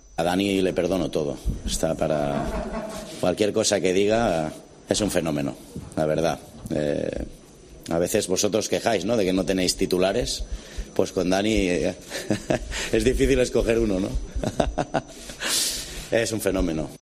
EN RUEDA DE PRENSA
El entrenador del Barça ha comparecido ante los medios de comunicación en la previa del partido ante el Levante y ha asegurado que Piqué también es duda para el jueves.